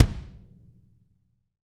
BD BD-0201R.wav